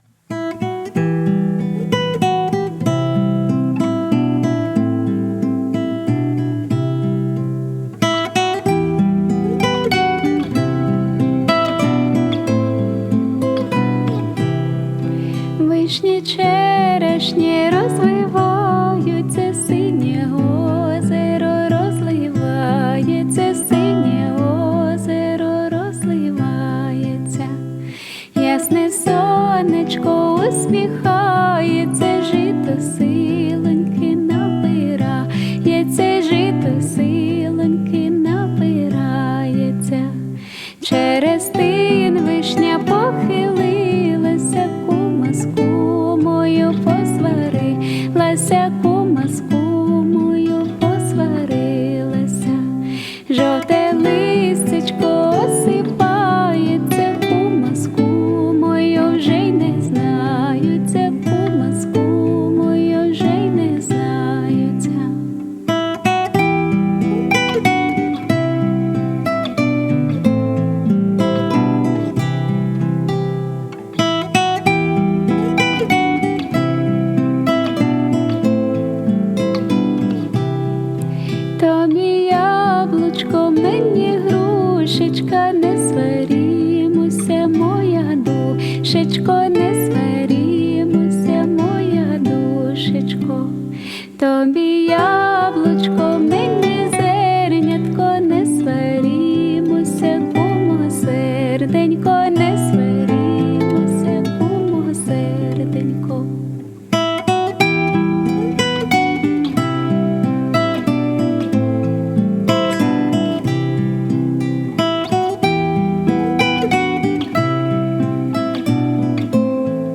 українська народна пісня